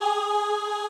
ChoirGsh.ogg